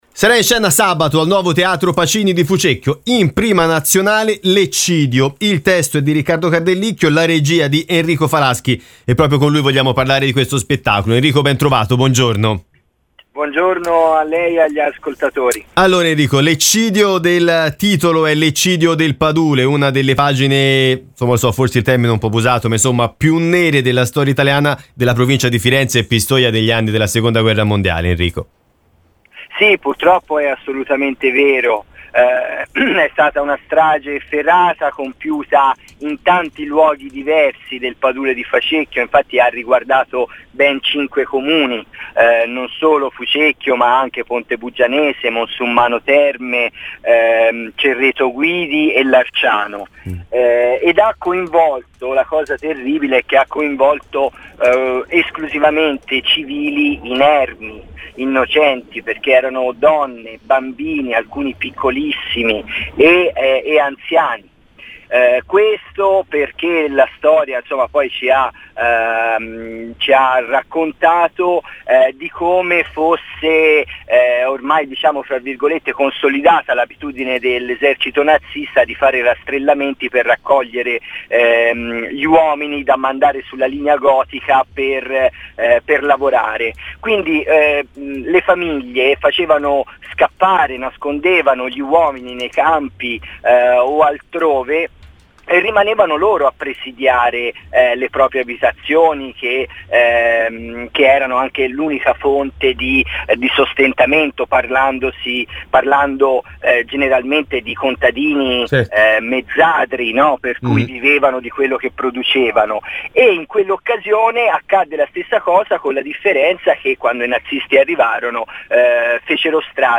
Interviste radiofoniche: